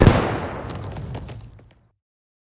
poison-capsule-explosion-2.ogg